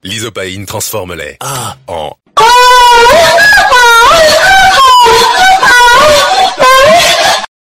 lysopaine ahh entier Meme Sound Effect